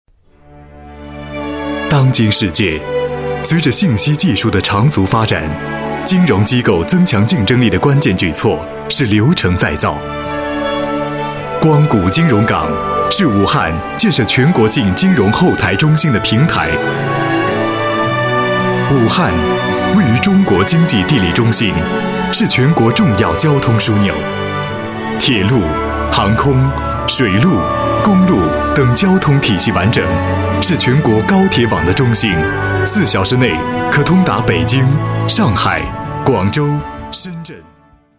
配音员：孙悦斌
配音风格： 大气 磁性